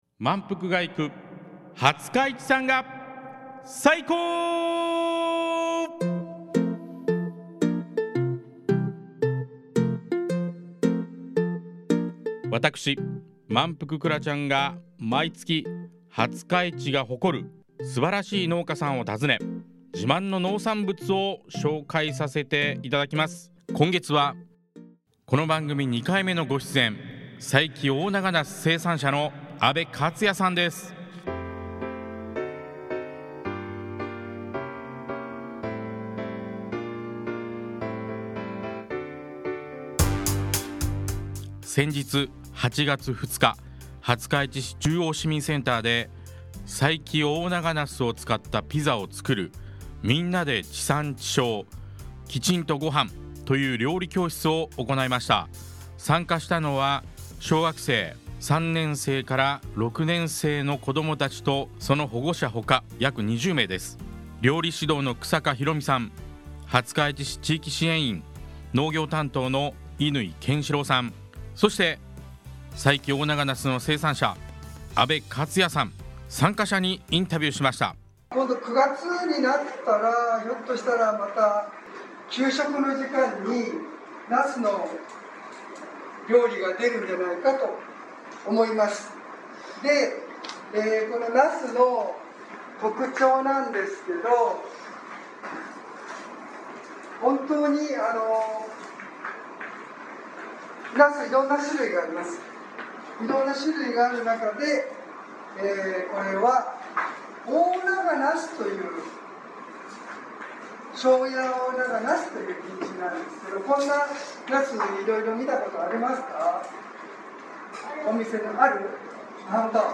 その模様とインタビュー！